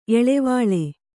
♪ eḷevāḷe